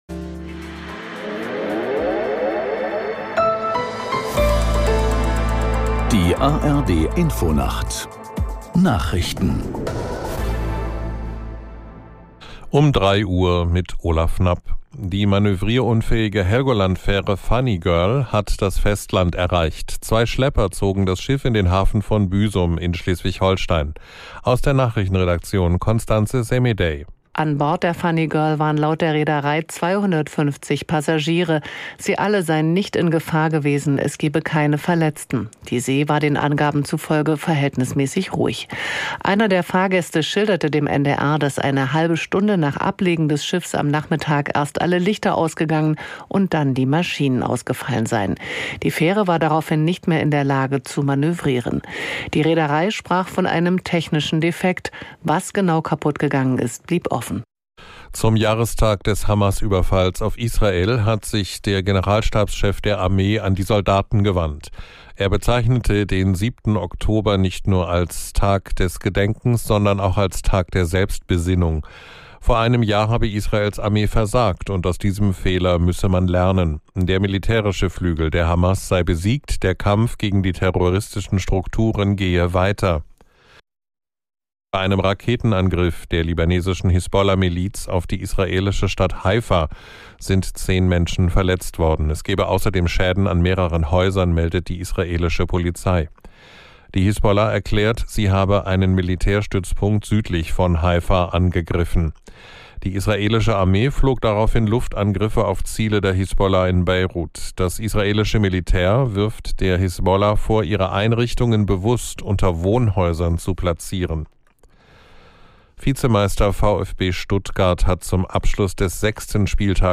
Nachrichten.